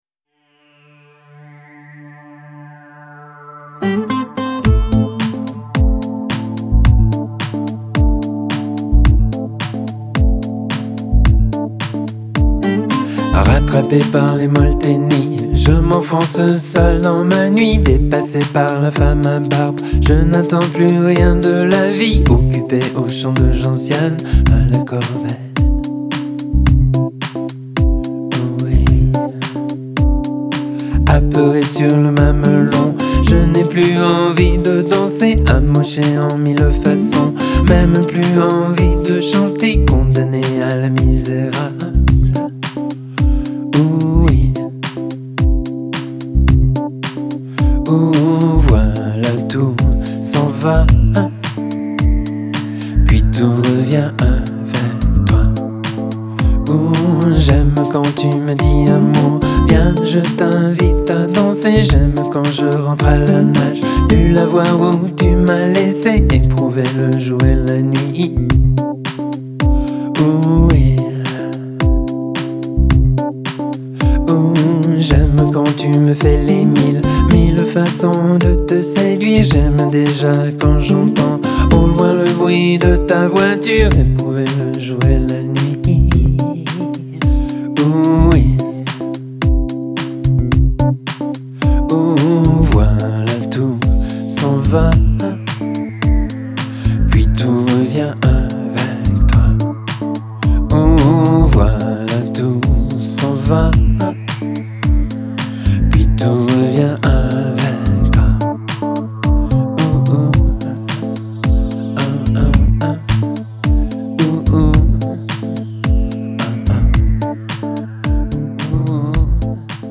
Genre : French Music